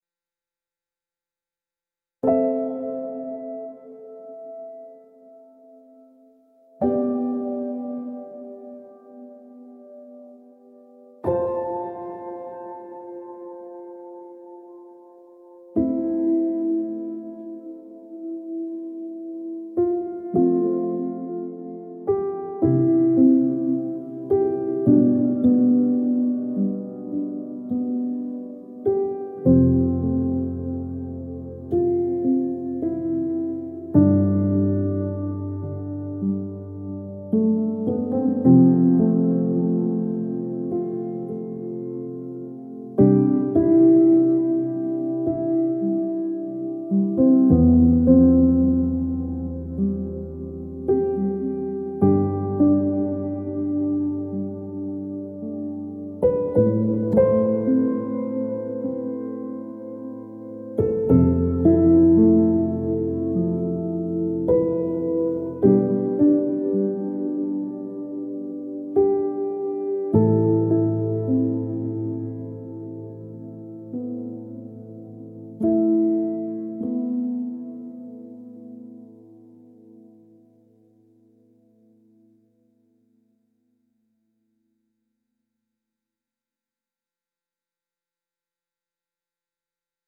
slow minimalist piano with long sustain and spacious reverb